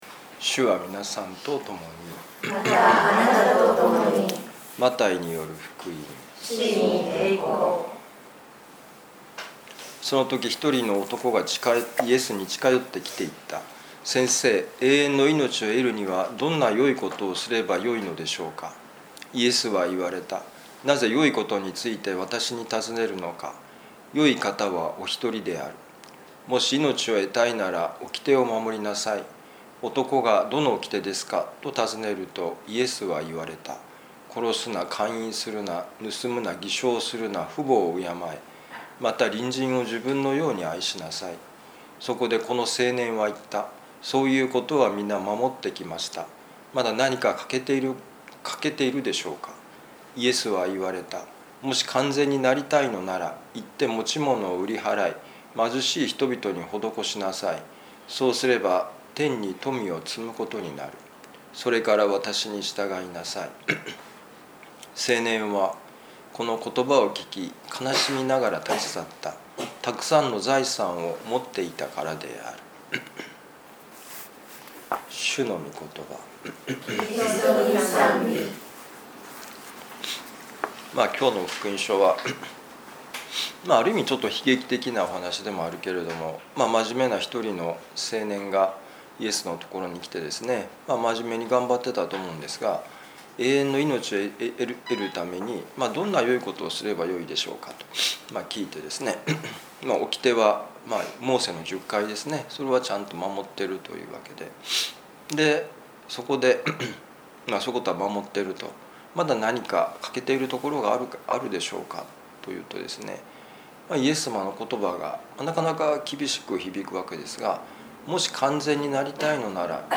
【ミサ説教】
マタイ福音書 19章16-22節「神が造られたありのままの姿で」2025年8月18日いやしのミサ旅路の里